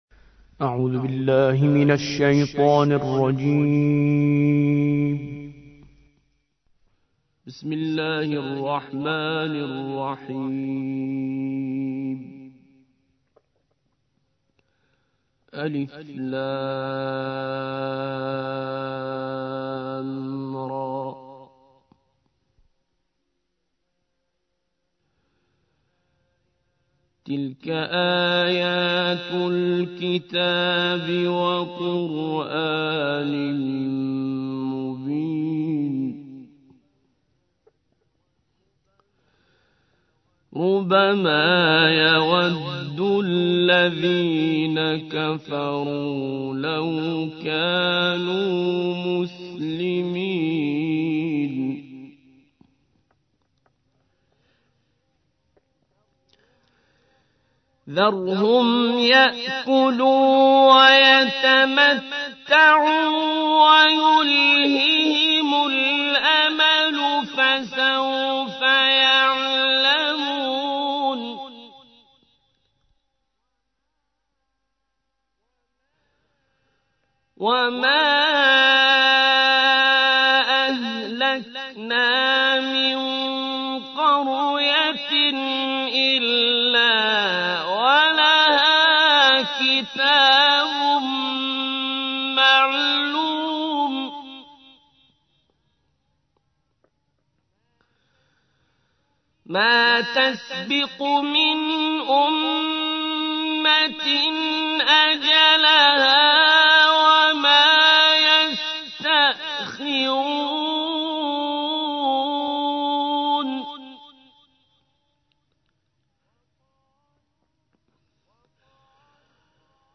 سورة الحجر / القارئ